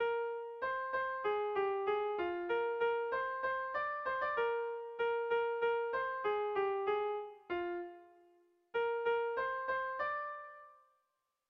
Dantzakoa
Zaldibia < Goierri < Gipuzkoa < Euskal Herria
Lauko txikia (hg) / Bi puntuko txikia (ip)